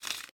mosaic_unlock.ogg